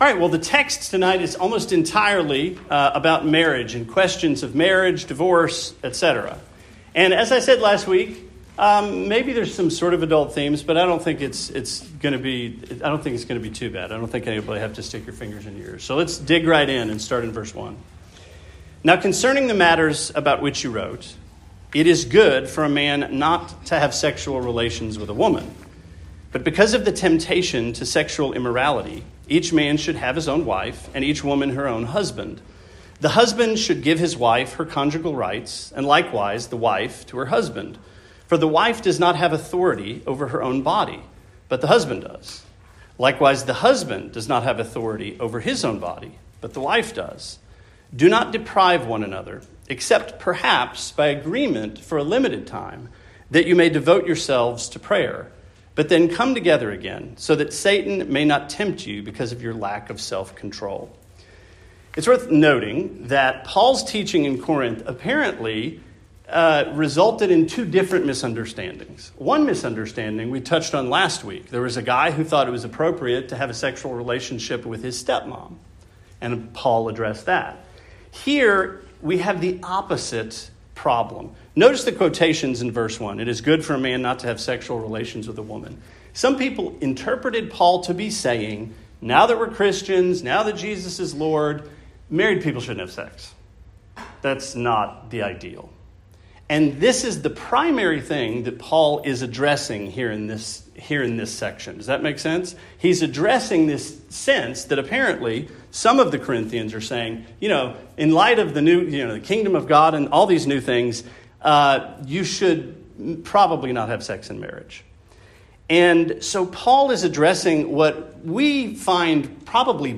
Sermon 4/30: 1 Corinthians 7: Questions About Marriage